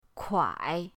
kuai3.mp3